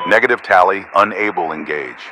Radio-pilotWingmanEngageNoTarget1.ogg